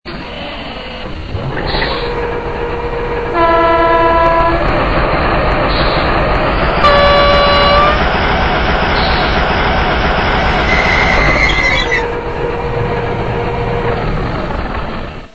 Dźwieki do lokomotyw PKP